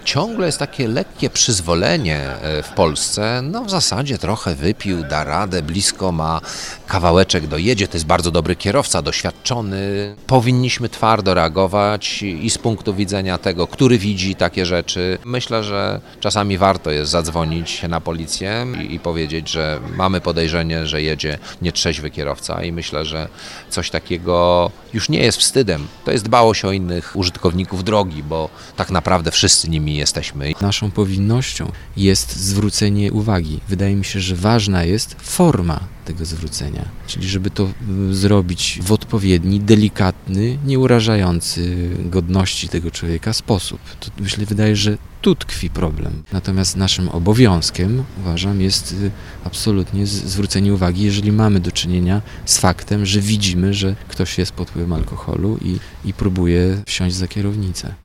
O to, by nie prowadzić samochodu pod wpływem alkoholu apelują również Krzysztof Hołowczyc i Radosław Pazura.